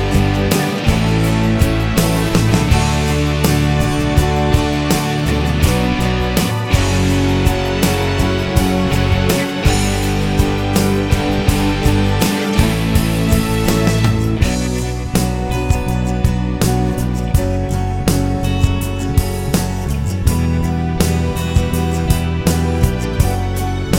No Piano Or Backing Vocals Pop (1990s) 4:03 Buy £1.50